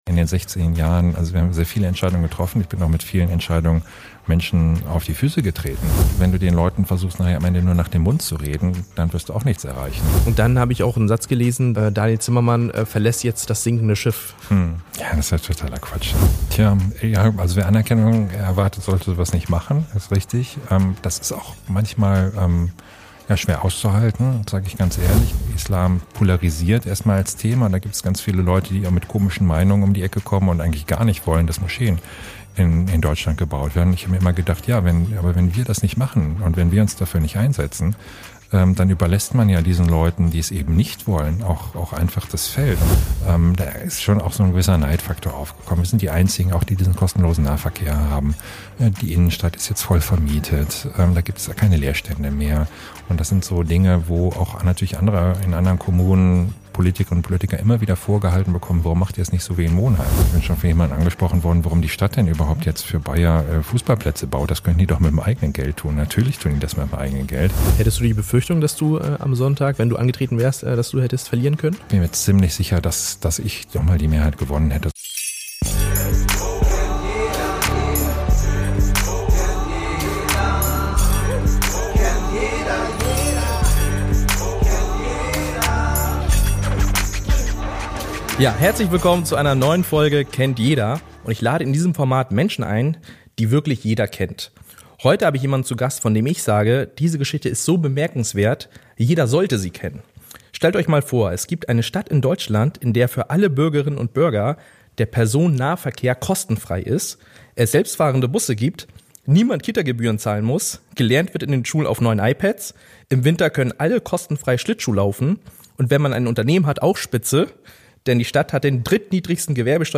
Beschreibung vor 7 Monaten Diesmal habe ich einen ganz besonderen Gast: den Bürgermeister von Monheim am Rhein - Daniel Zimmermann.
In diesem Gespräch spreche ich mit ihm über seine außergewöhnliche Karriere, seine Visionen für die Zukunft und was als Nächstes ansteht.